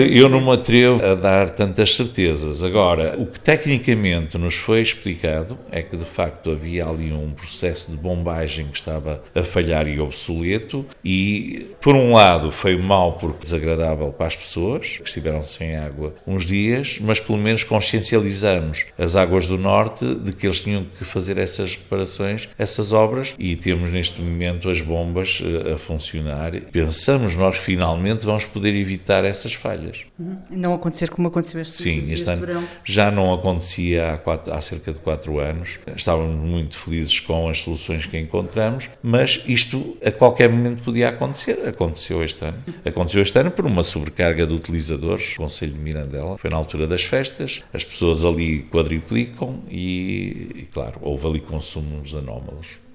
Benjamim Rodrigues não garante que não volte a faltar:
reuniao-de-camara-4.mp3